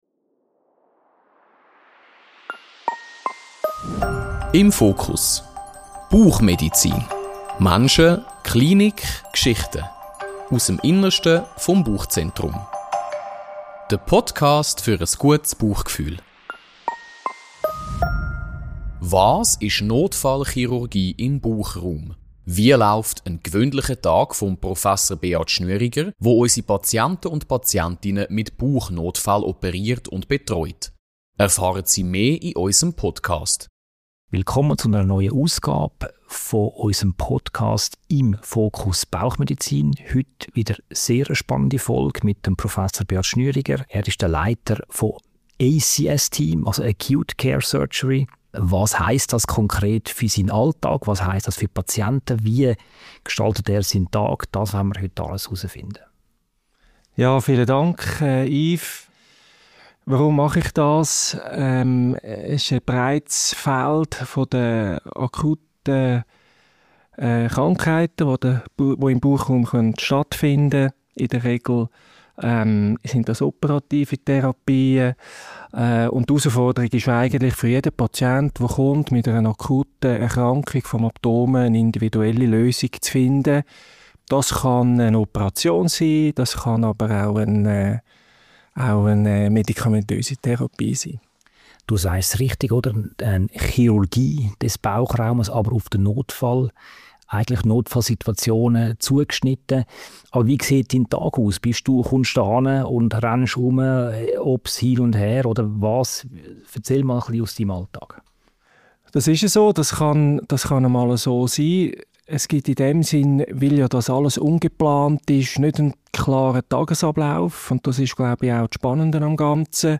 Ein spannendes und zugleich sachliches Gespräch über Priorisierung, Entscheidungsfindung und moderne minimalinvasive Konzepte in der Akutchirurgie.